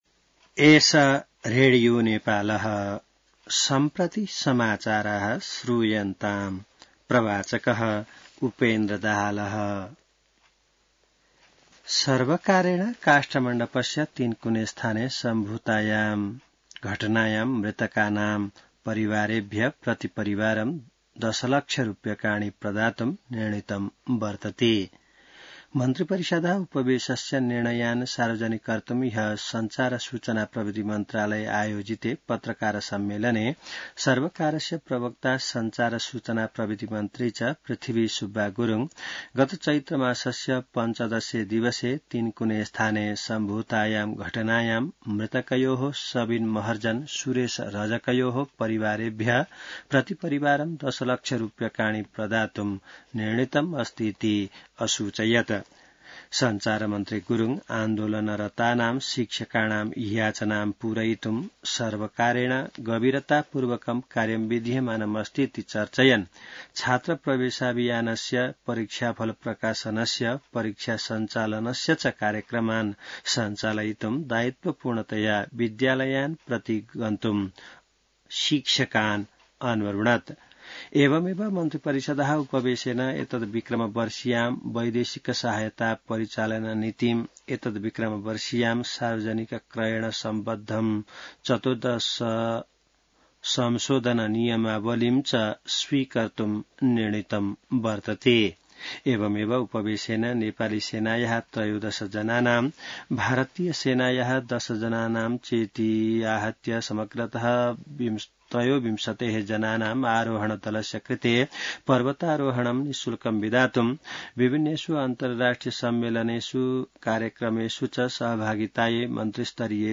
संस्कृत समाचार : ११ वैशाख , २०८२